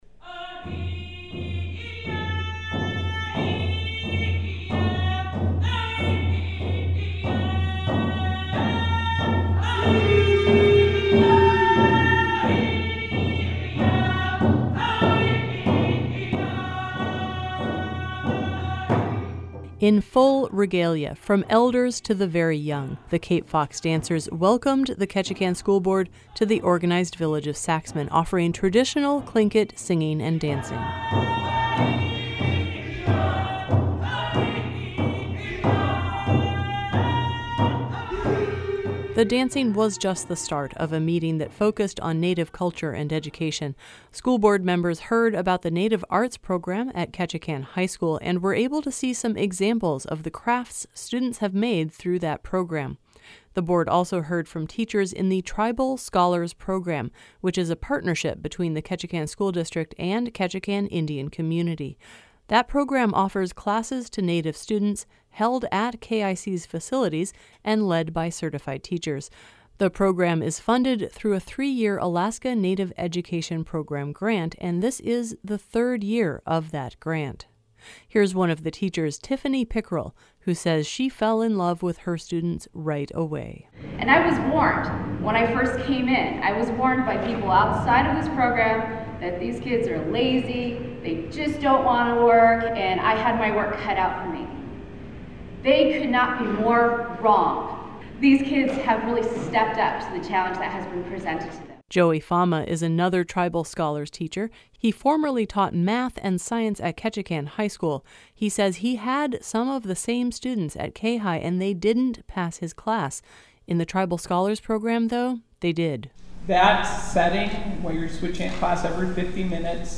The Ketchikan School Board honored Native culture Wednesday in recognition of Alaska Native Heritage Month. The board met at the Saxman Community Center, and the Cape Fox Dancers kicked off the special meeting with dancing, drumming and singing.
In full regalia, from elders to the very young, the Cape Fox Dancers welcomed the Ketchikan School Board to the Organized Village of Saxman, offering traditional Tlingit singing and dancing.